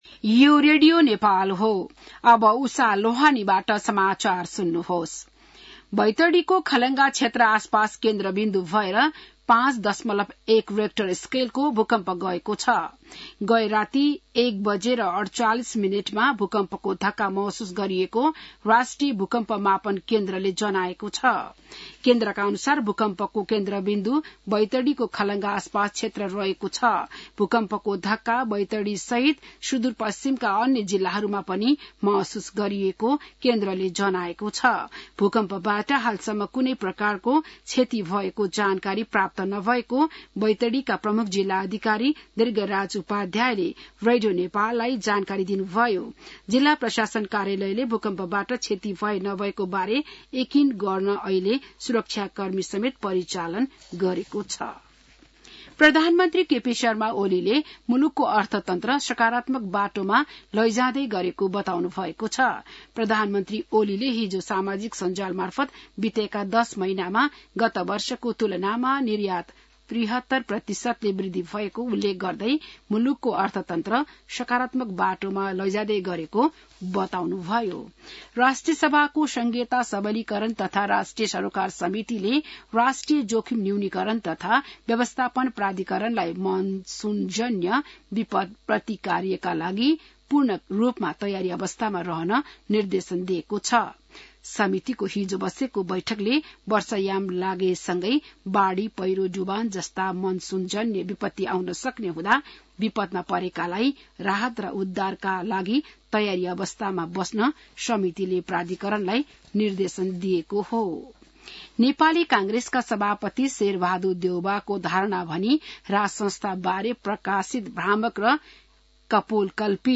बिहान १० बजेको नेपाली समाचार : ९ जेठ , २०८२